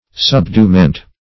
subduement - definition of subduement - synonyms, pronunciation, spelling from Free Dictionary Search Result for " subduement" : The Collaborative International Dictionary of English v.0.48: Subduement \Sub*due"ment\, n. Subdual.